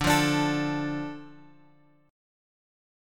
Dm chord {x 5 3 x 3 5} chord
D-Minor-D-x,5,3,x,3,5.m4a